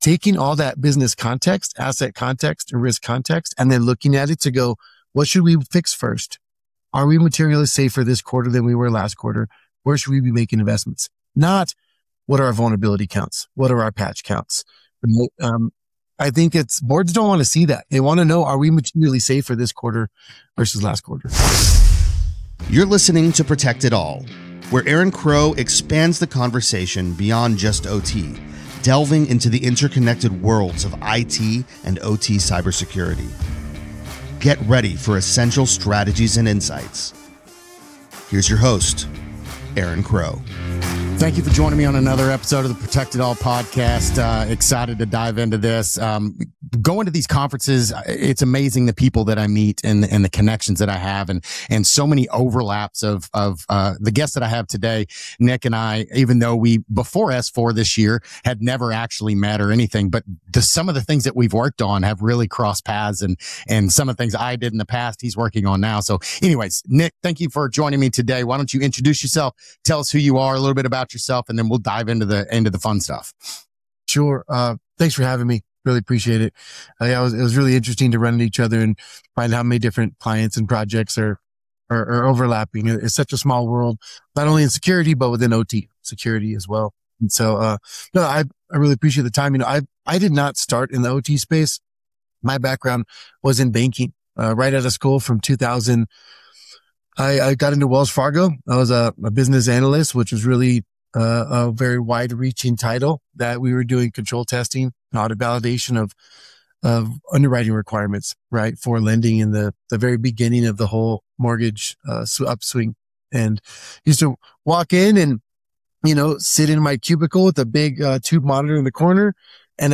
This conversation dives into one of the biggest challenges in OT today: asset visibility and risk quantification .